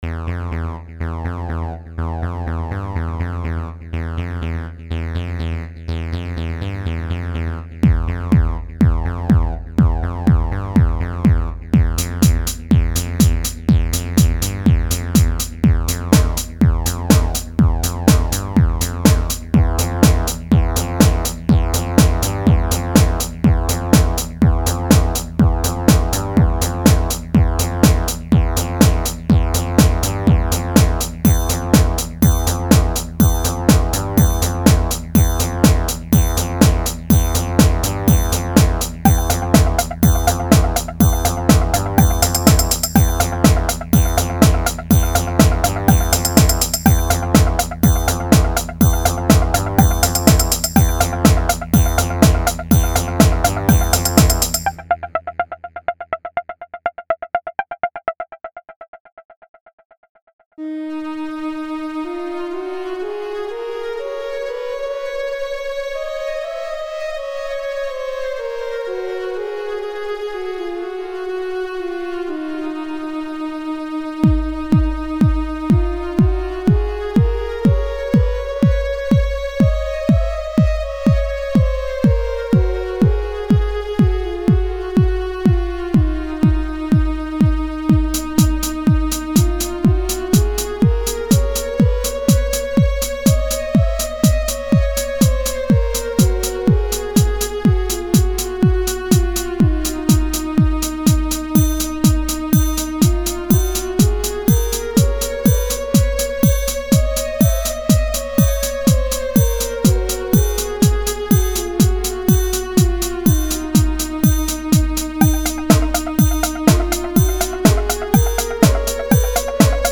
TBA promo demo!!
Its a remake.